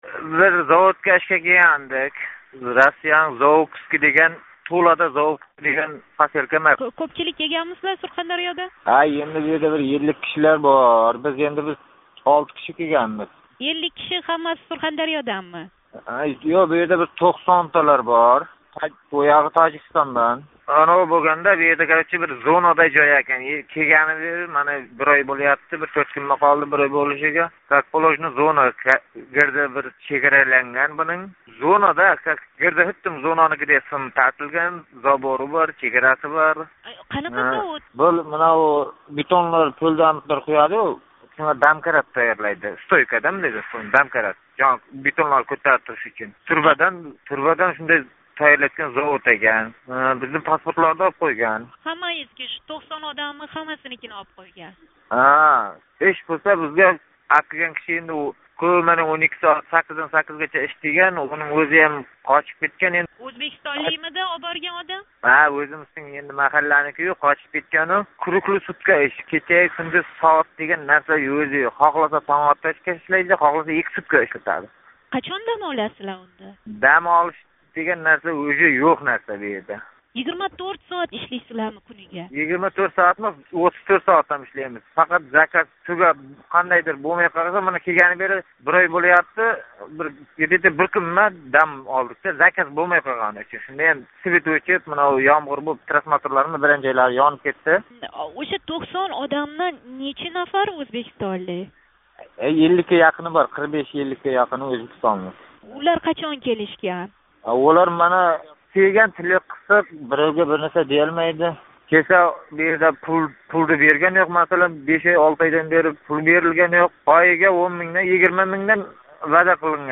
Туладаги ўзбек билан суҳбат